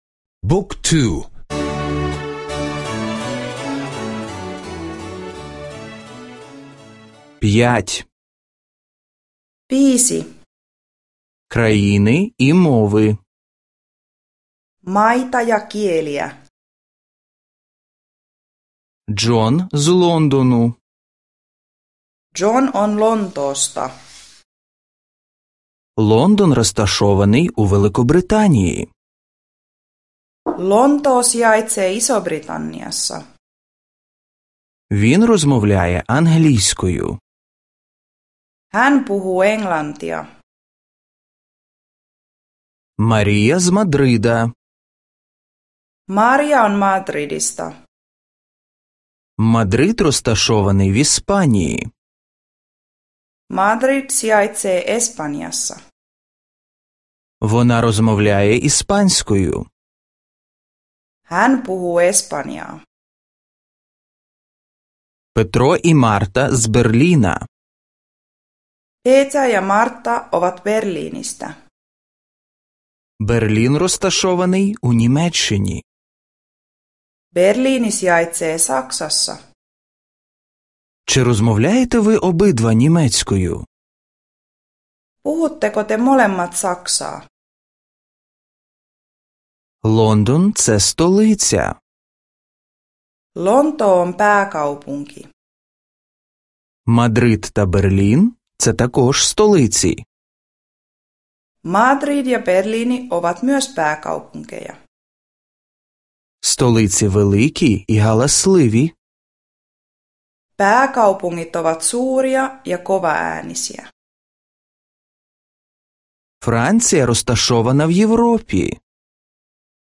Аудіо уроки фінської мови — завантажити безкоштовно